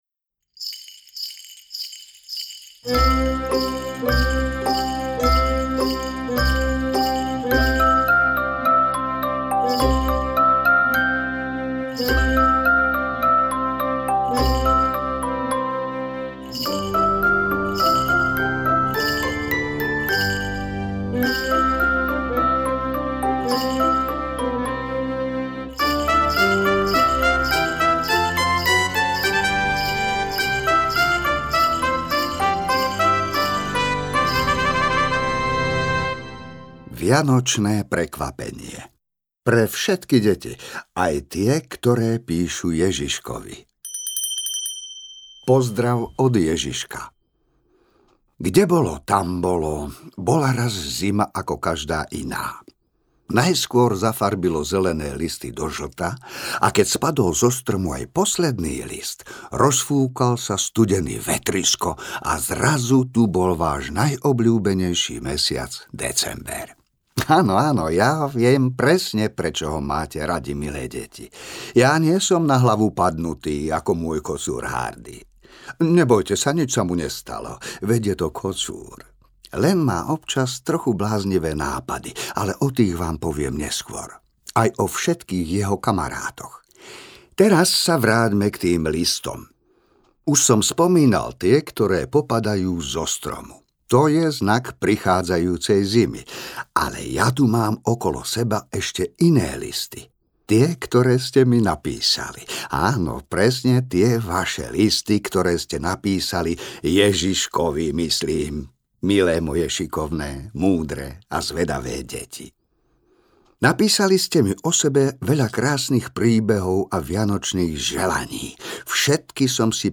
Audiokniha pre deti
Žáner: Rozprávky
BMT studio 2019